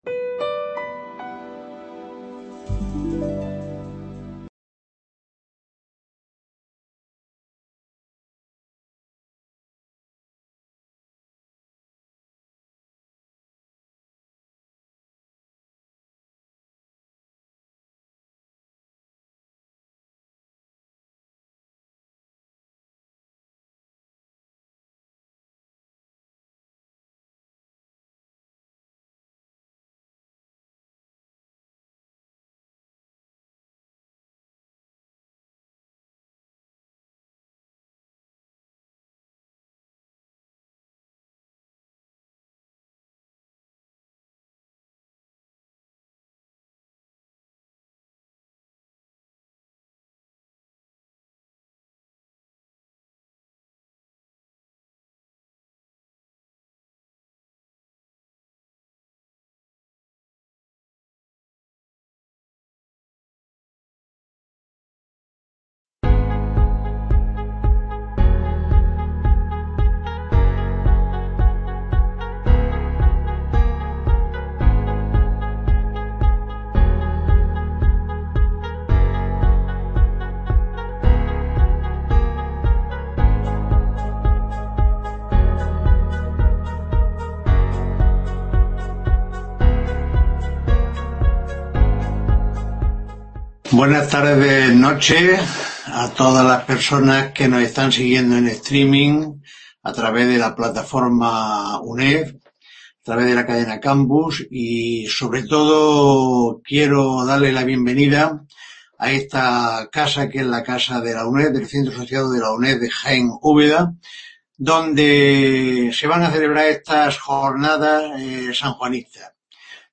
imparte esta conferencia en el auditorio del Hospital de Santiago de Úbeda